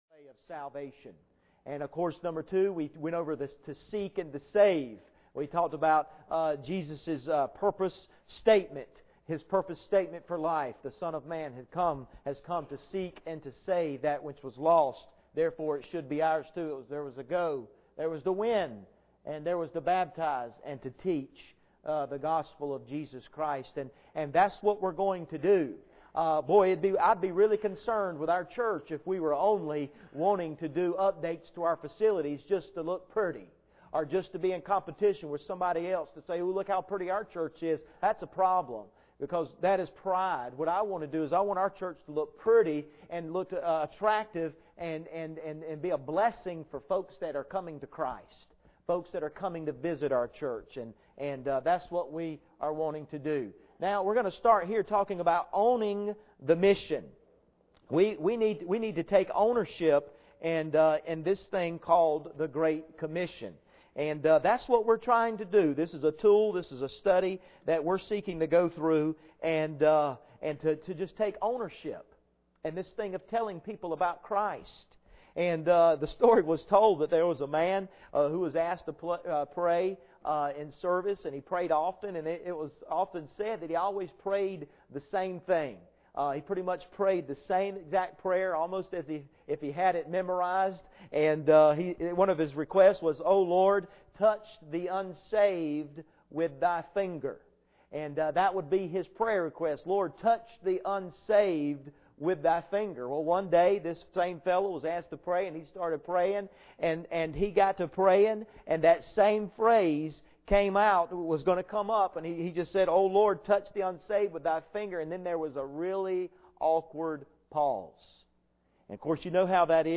Bible Text: Luke 10:1 | Preacher: 2010 Missionary Panel | Series: Take It Personally
Service Type: Sunday Evening